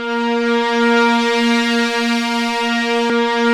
P.5 A#4 7.wav